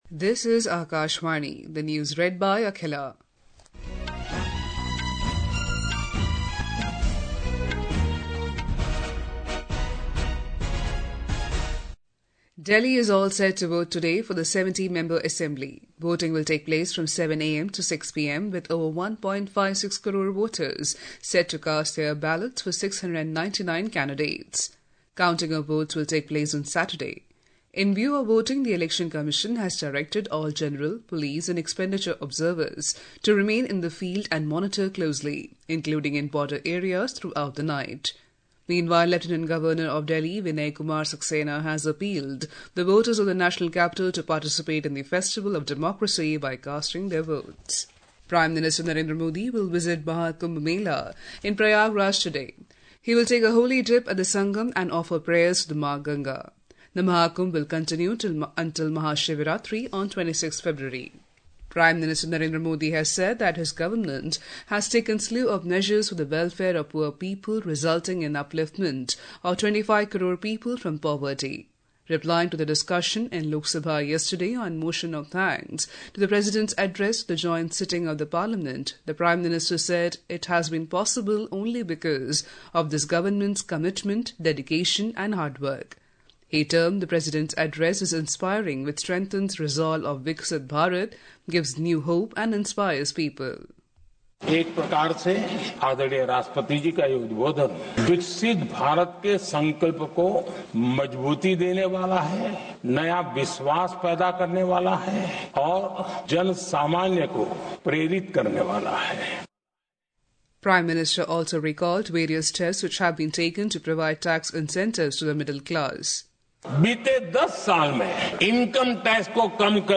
Hourly News